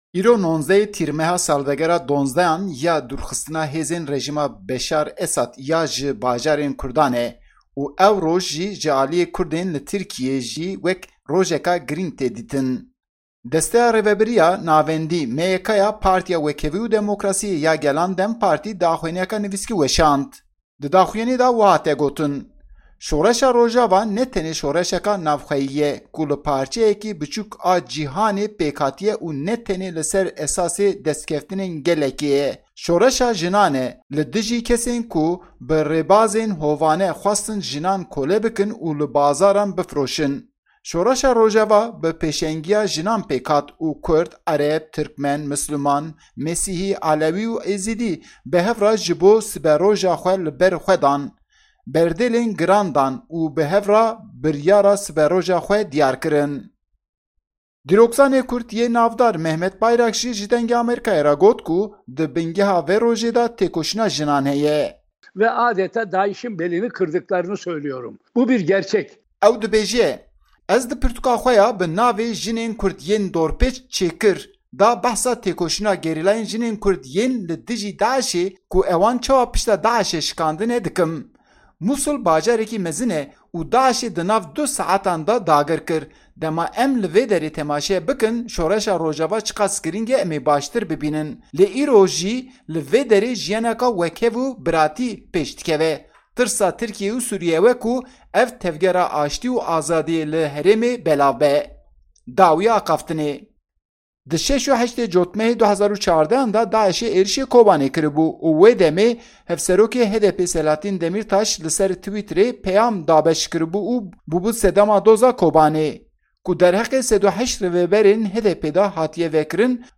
Parlementerê DEM Partî Yilmaz Hun jî got ku 19'ê Tîrmehê rizgarbûna gelên herêmê ye.